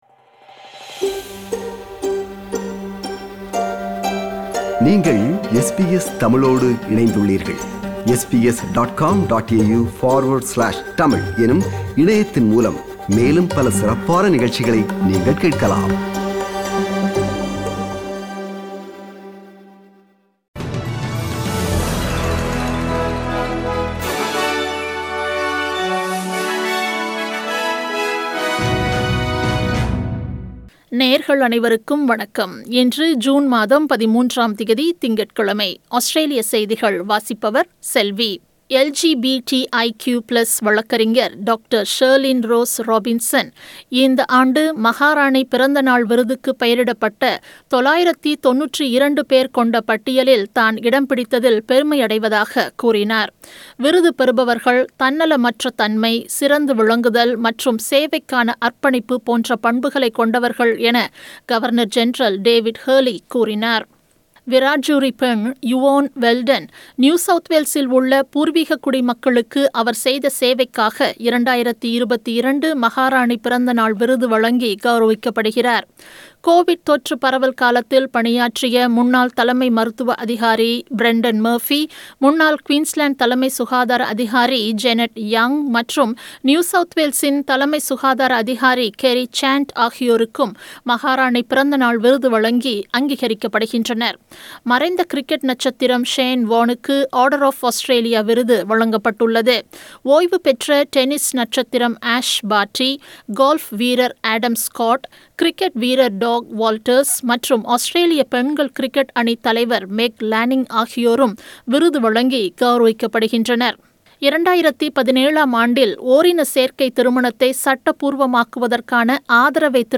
Australian news bulletin for Monday 13 June 2022.